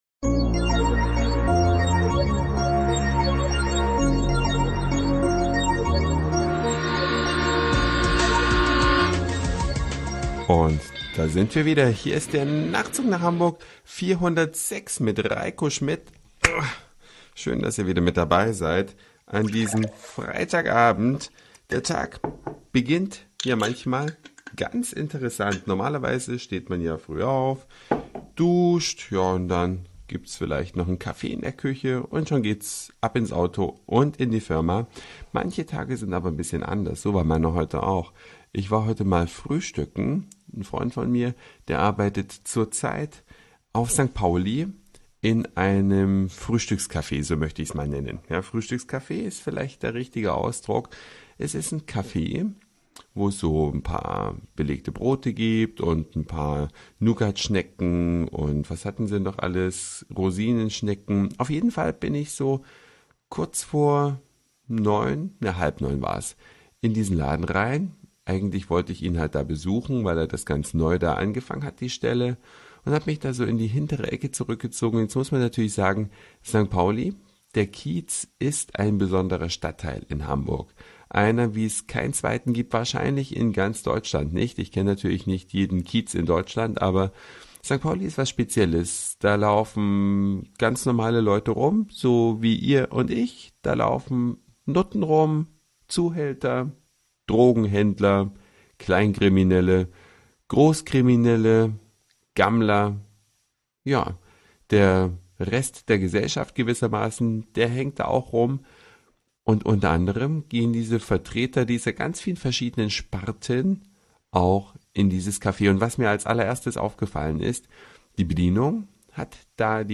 Eine Reise durch die Vielfalt aus Satire, Informationen, Soundseeing und Audioblog.
Frühstück in einem Café auf dem Kiez: St. Paulis Bewohner zu Gast